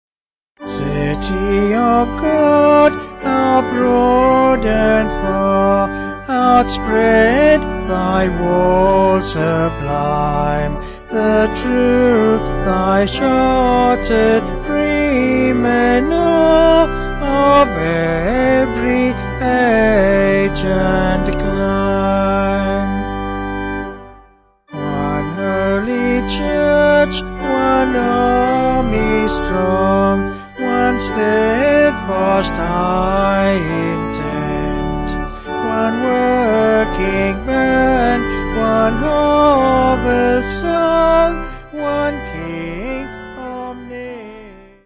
Vocals and Organ
262.9kb Sung Lyrics 2.1mb